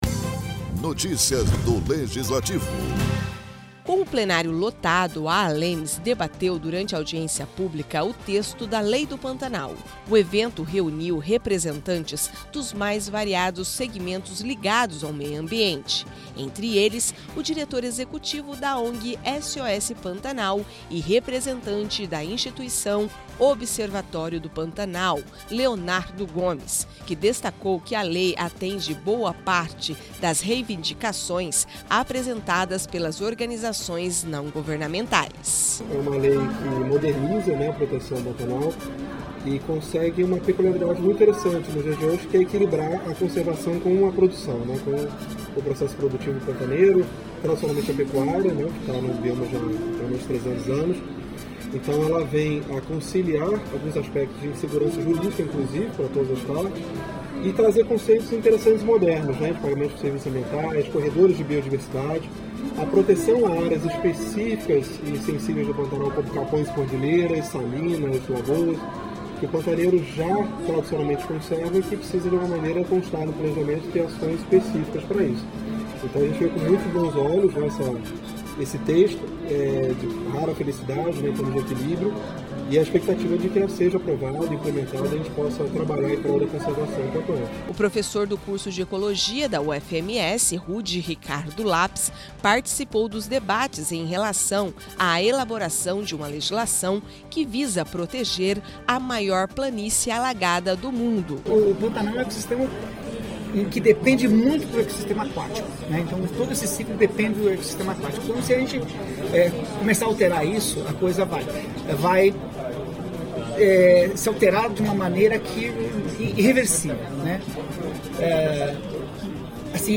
Com o plenário lotado a Assembleia Legislativa de Mato Grosso do Sul (MS) debateu, durante audiência pública, o texto da Lei do Pantanal. O evento reuniu representantes dos mais variados segmentos ligados ao meio ambiente.